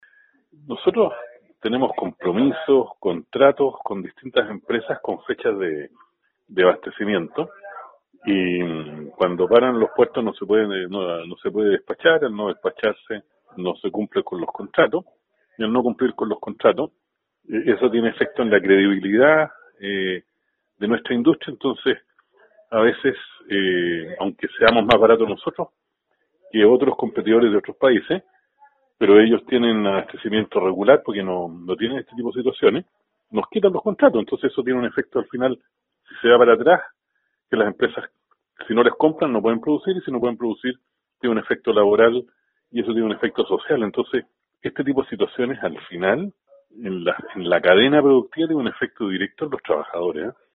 en conversación con Radio Sago indicó que la paralización del sector portuario ha impedido el despacho de embarques del producto al exterior